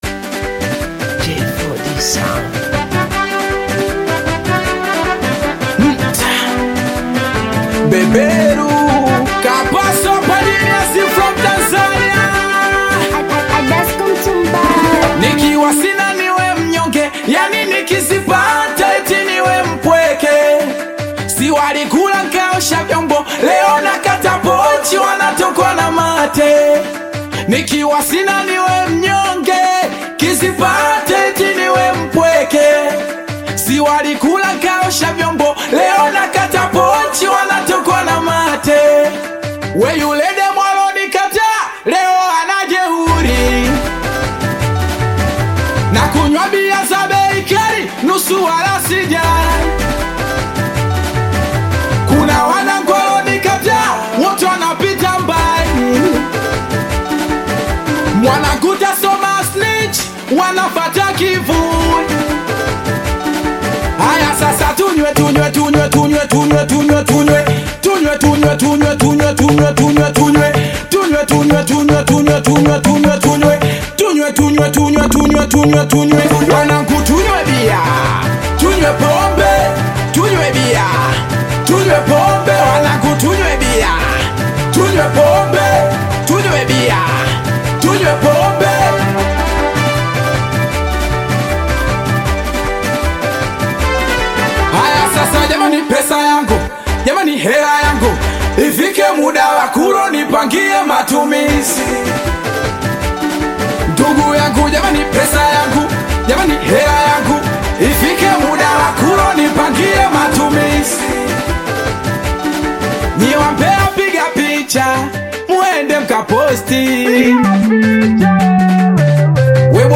Tanzanian Bongo Flava singeli